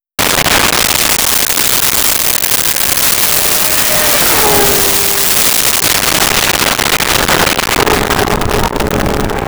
Top Fuel Car Fast By Single
Top Fuel Car Fast By Single.wav